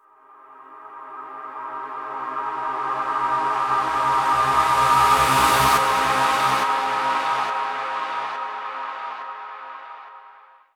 VEC3 FX Athmosphere 02.wav